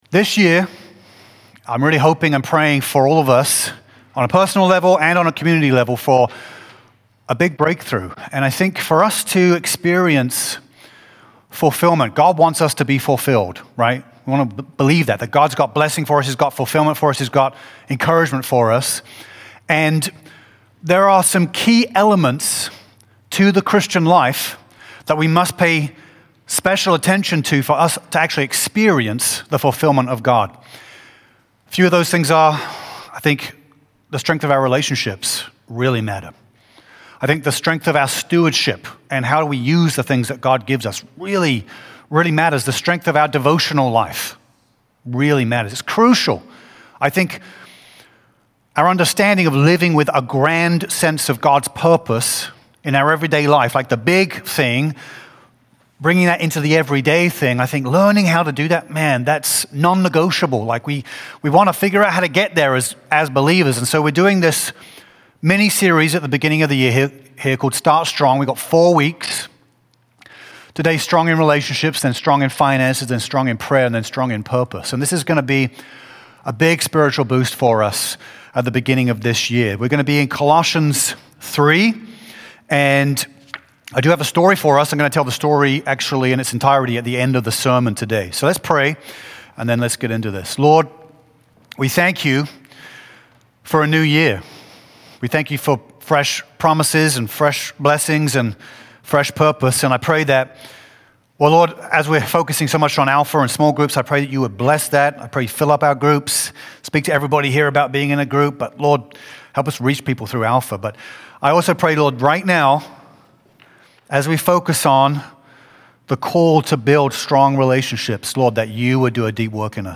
A message from the series "Start Strong."
JAN-4-2026-Full-Sermon.mp3